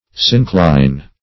syncline - definition of syncline - synonyms, pronunciation, spelling from Free Dictionary Search Result for " syncline" : The Collaborative International Dictionary of English v.0.48: Syncline \Syn*cline"\, n. (Geol.)